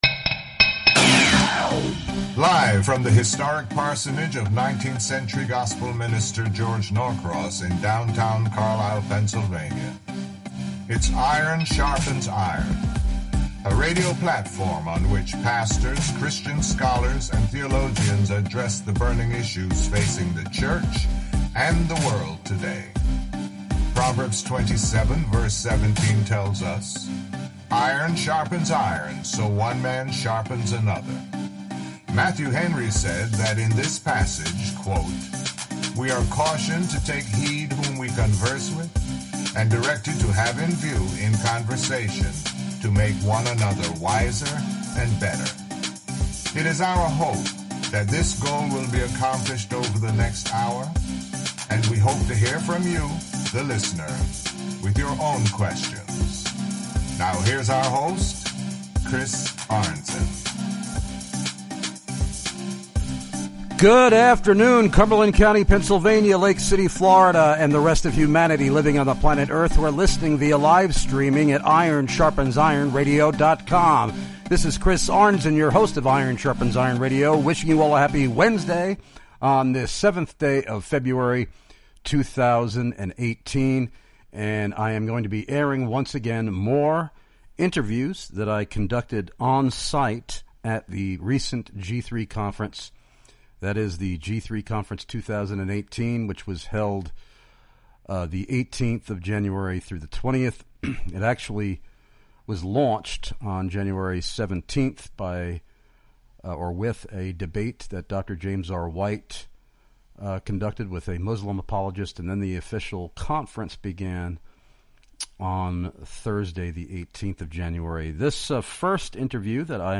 Interviews recorded LIVE at the 2018 G3 CONFERENCE!!!
These interviews were conducted on-site from the Iron Sharpens Iron Radio booth in the exhibition hall of the Georgia International Convention Center in Atlanta.